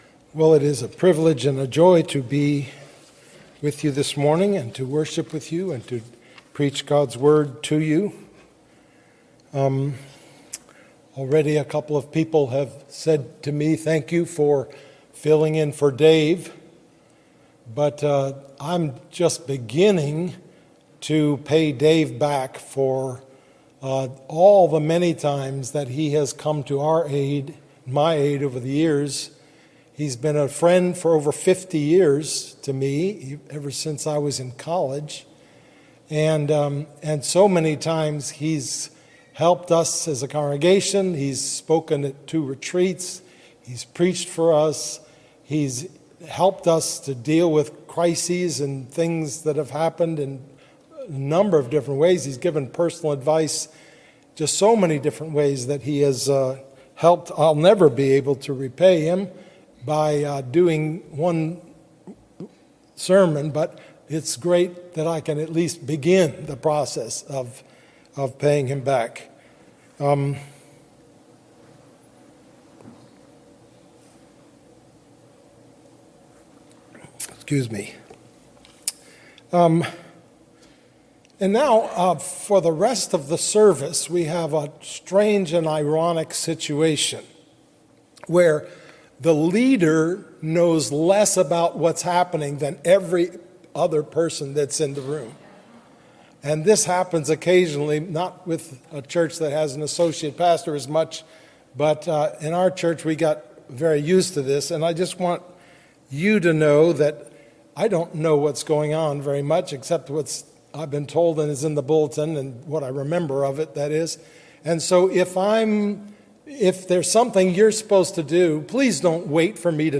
A Strange Encounter with Jesus: Sermon on Matthew 15:21-28 - New Hope Presbyterian Church